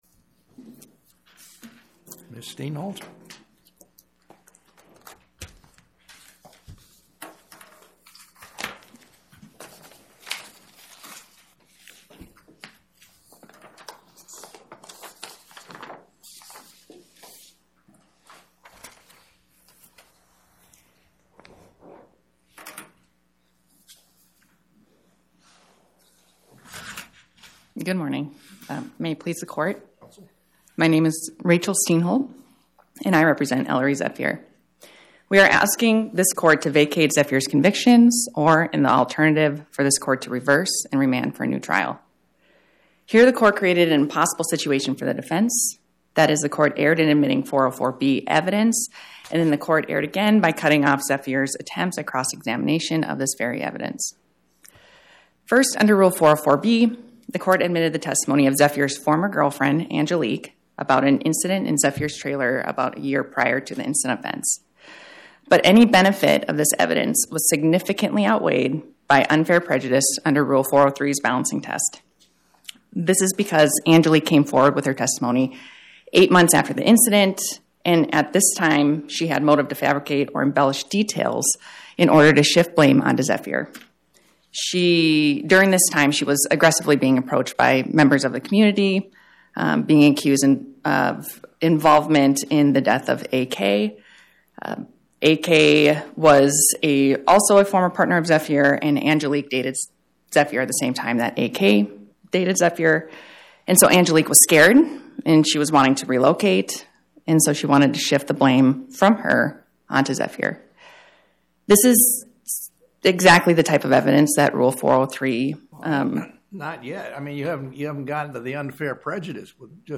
Oral argument argued before the Eighth Circuit U.S. Court of Appeals on or about 02/12/2026